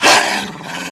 sounds / monsters / dog / attack_hit_2.ogg
attack_hit_2.ogg